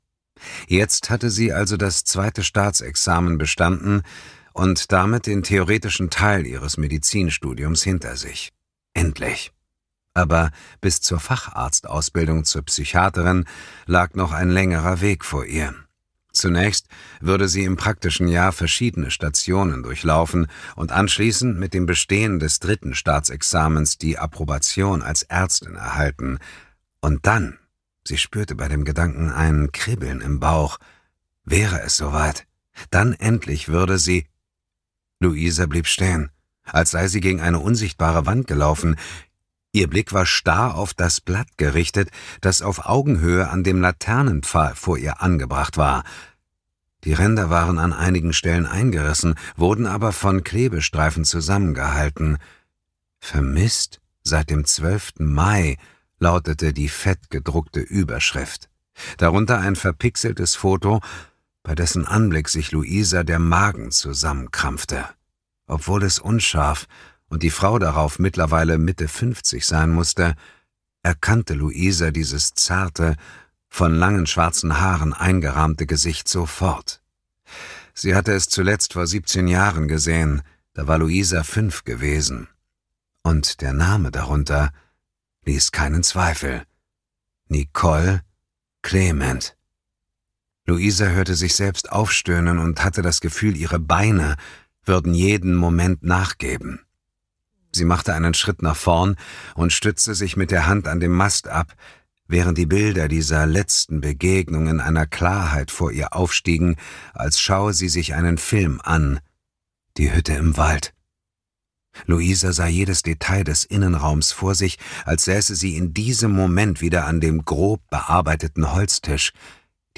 Ungelöst – Die erste Zeugin - Arno Strobel | argon hörbuch
Gekürzt Autorisierte, d.h. von Autor:innen und / oder Verlagen freigegebene, bearbeitete Fassung.
Ungelöst – Die erste Zeugin Gelesen von: Dietmar Wunder